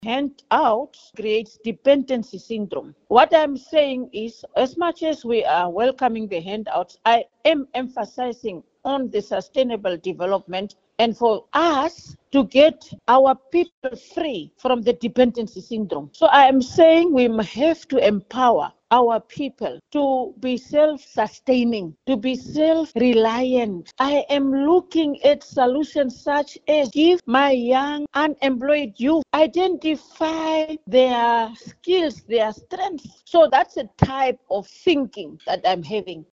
Speaking at a stakeholder workshop in Swakopmund, she urged industry leaders to commit to long-term, community-building investments rather than symbolic gestures.